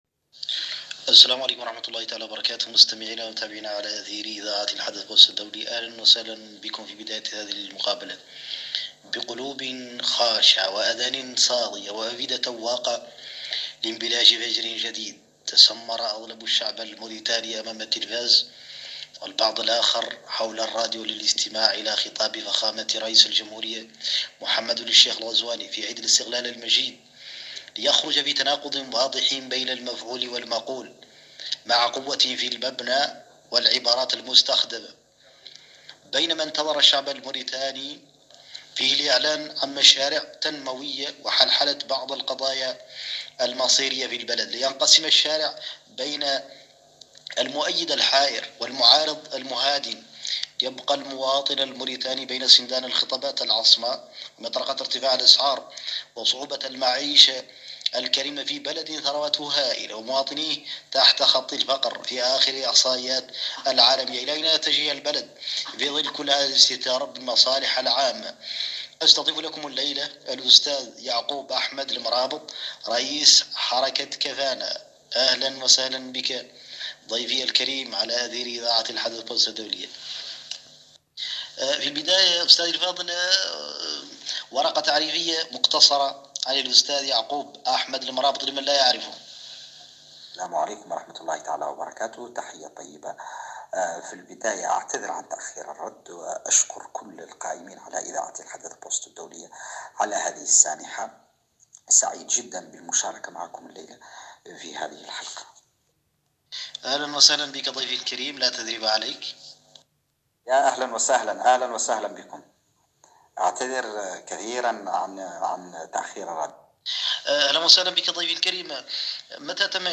مقابلات